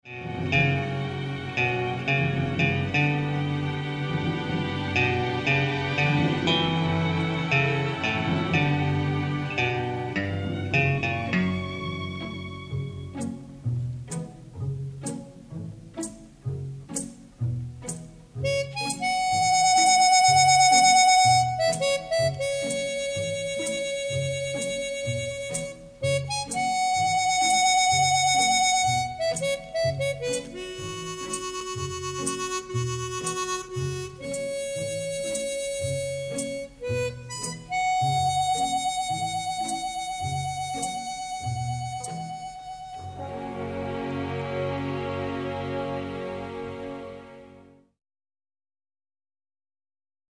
Spaghetti Western exciting medium instr.